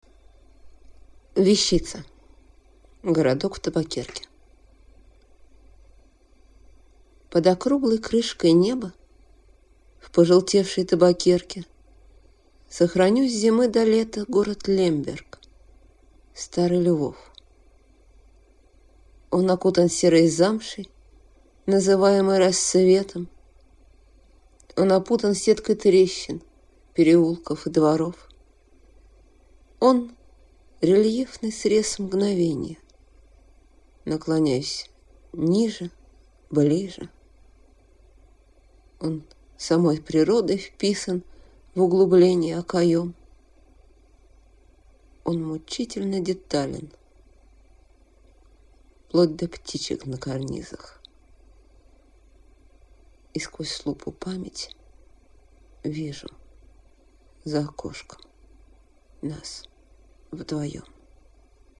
Стихотворение, которое ему нравится, ему посвящается, да и написано было (как многие мои стихи), в первую очередь, для него.